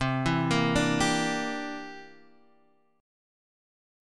C9 chord